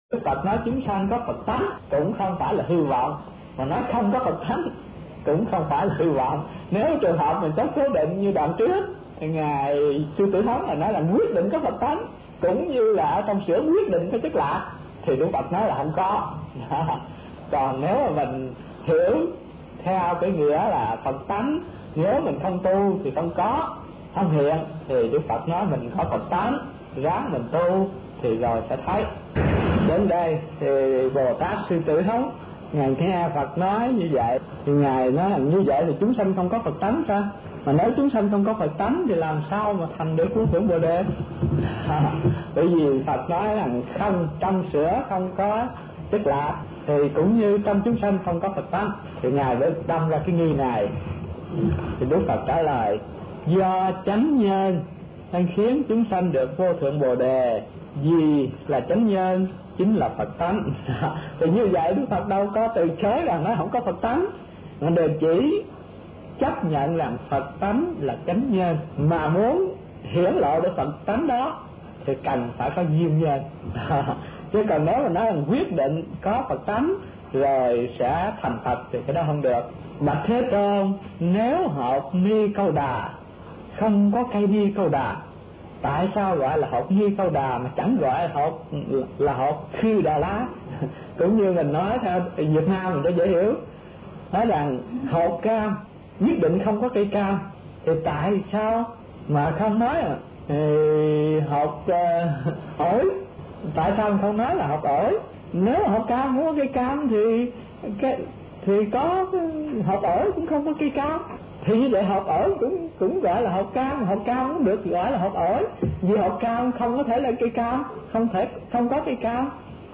Kinh Giảng Đại Bát Niết Bàn - Thích Thanh Từ